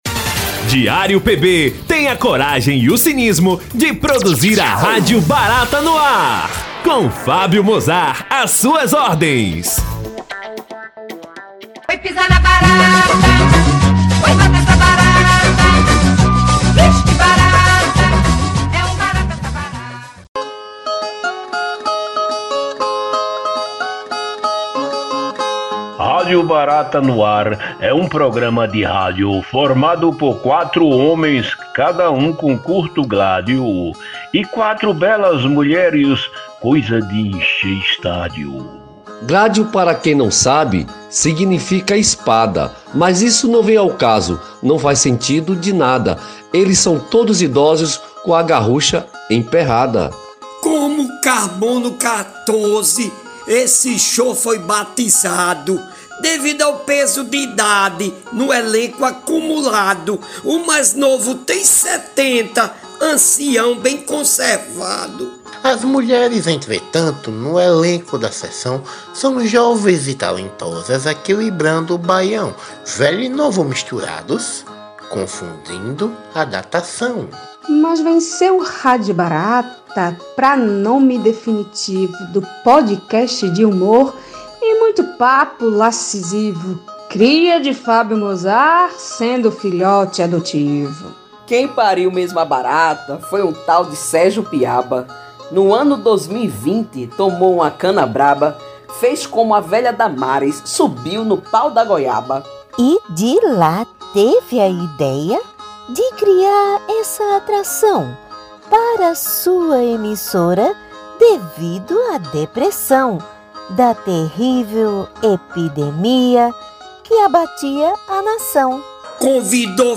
O objetivo do programa é abordar assuntos do cotidiano, comentar de maneira bem humorada, os acontecimentos da semana, de forma irreverente e leve, sempre com boas piadas.